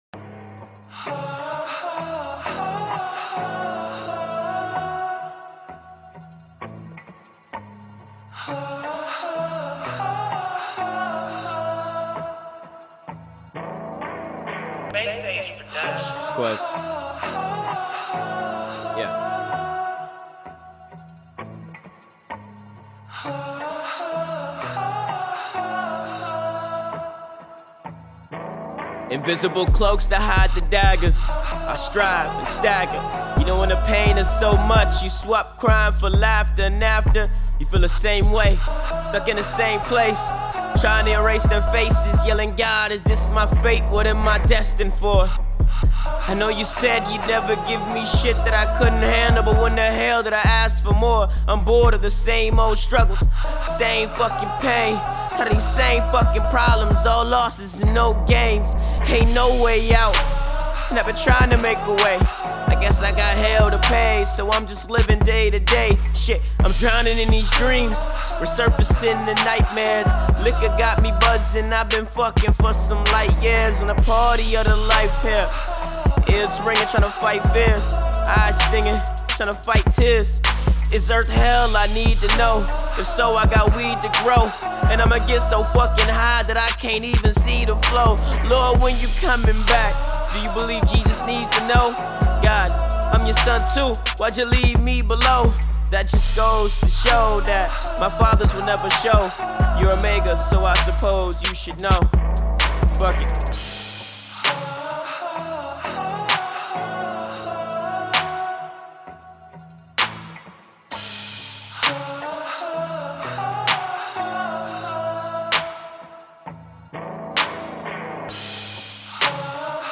Mixed Myself. Need some input.